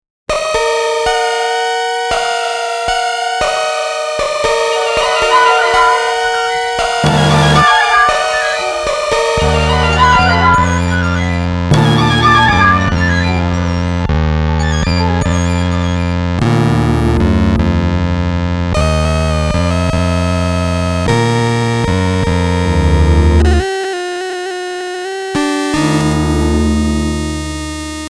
Ультраредкий детский синтезатор Грифон от НПСЦ «Центурион» | Children’s synthesizer Gryphon from NPSC «Centurion». Содержит 160мб. семплов в 4х пресетах: 2 оригинальных, замедленный и оркестровый. В силу дефектов обладает пугающим специфическим звучанием.
Gryphon-Synth.mp3